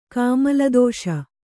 kāmaladōṣa